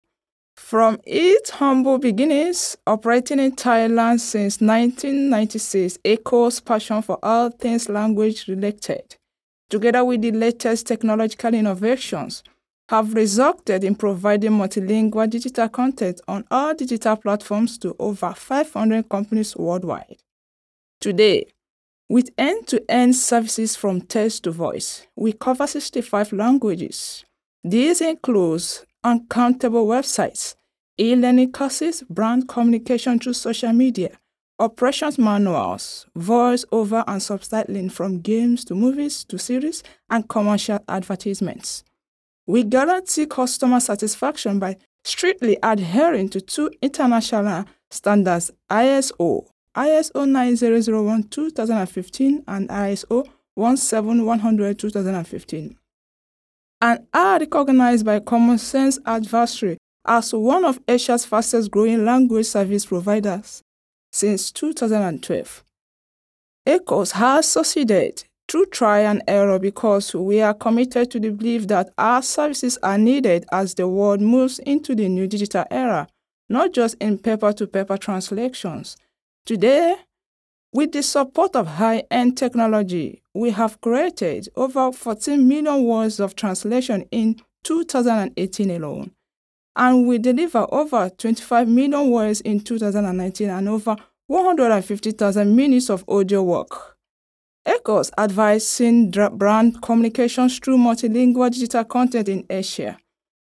Voiceover Artists
English (Generic) Female 03801
NARRATION